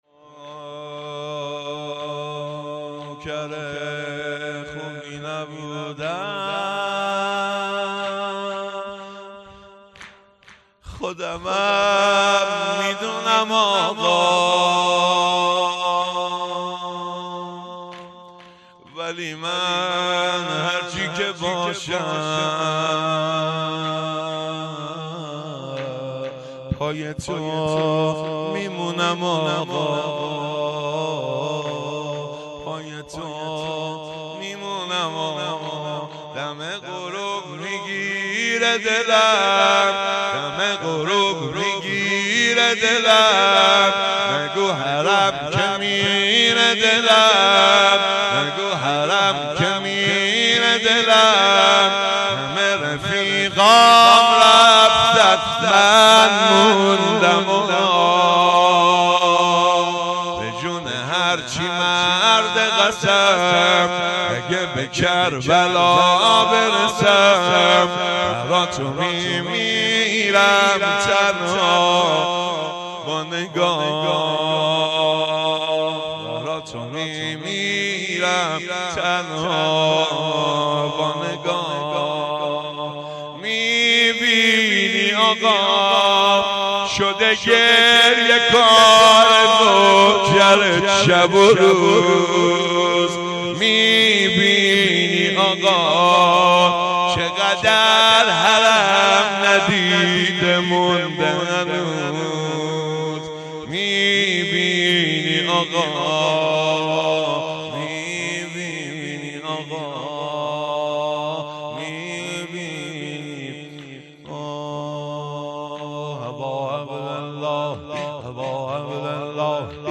شور
مراسم شهادت امام جعفر صادق علیه السلام ۱۴۰۳